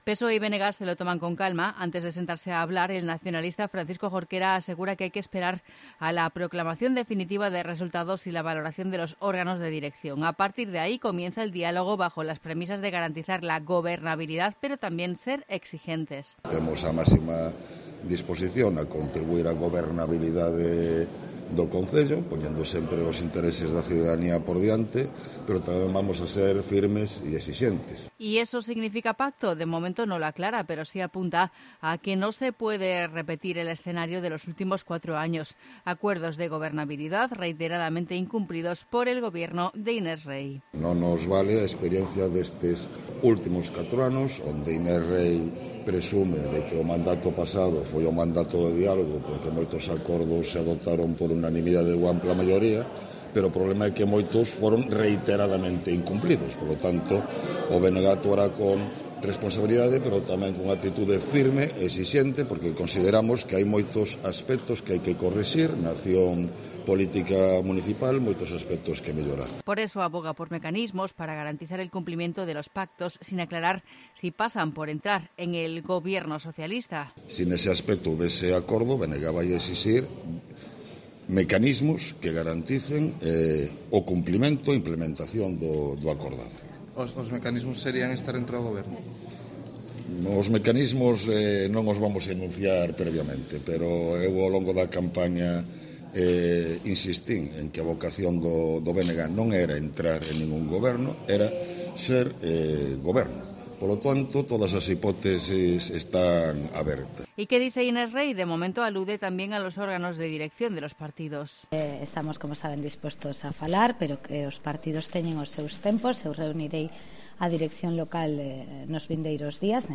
¿Habrá pacto PSOE-BNG en A Coruña? Hablan Inés Rey y Francisco Jorquera